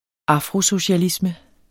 Udtale [ ˈɑfʁo- ]